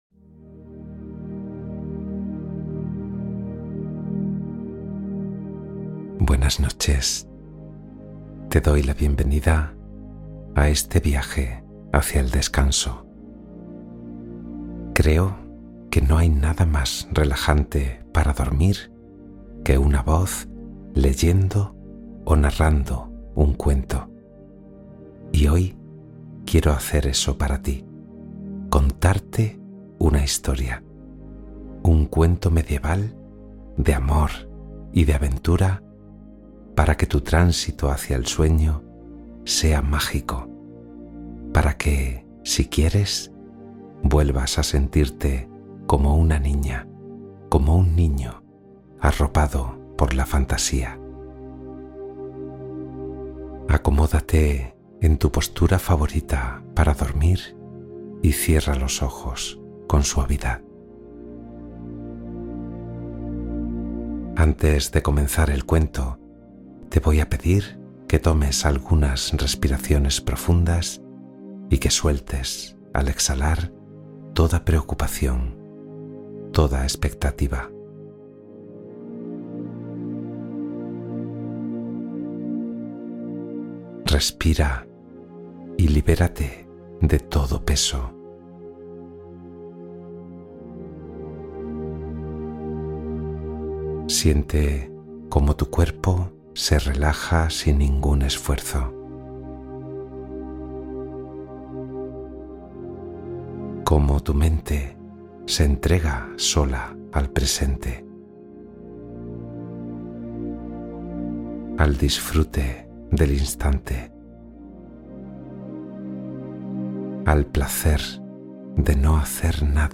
Relajación y cuento para dormir profundamente y sanar el alma